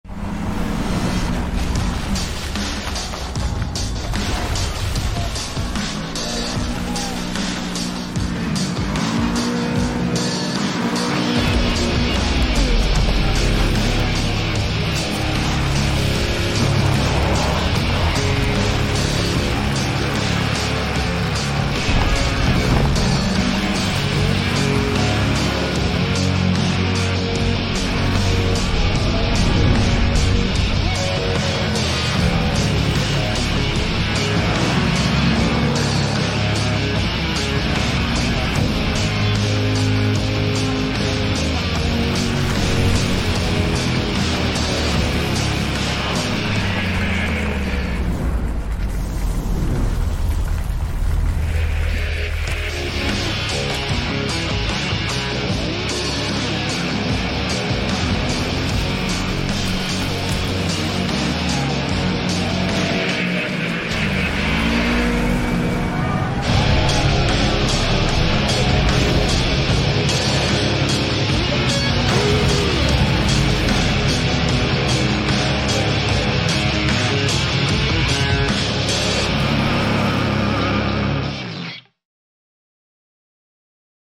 Kawasaki 2025 Teryx KRX4 1000 sound effects free download
Kawasaki 2025 Teryx KRX4 1000 Lifted